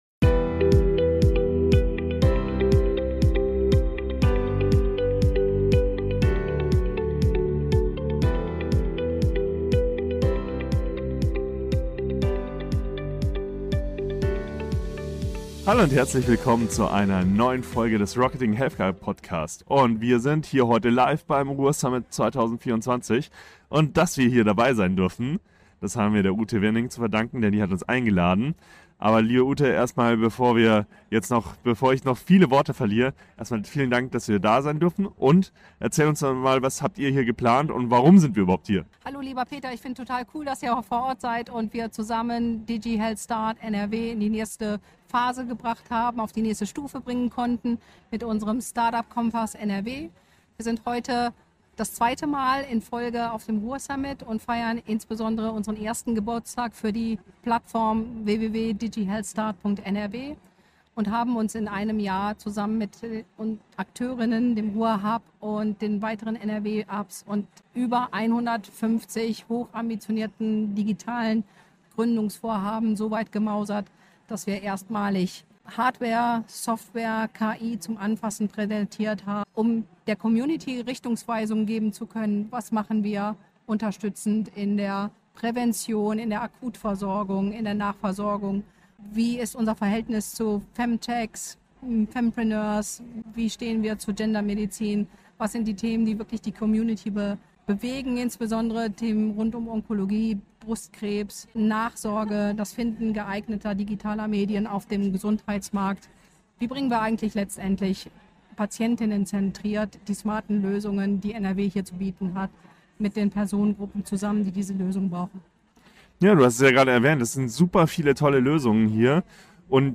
In dieser Sonderfolge direkt vom ruhrSUMMIT 2024 haben wir spannende Einblicke in die digitale Gesundheitsbranche erhalten und diskutieren über die neuesten Trends und Herausforderungen.